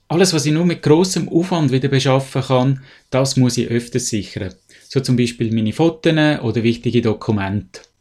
Dieses Interview gibt es auch auf Hochdeutsch!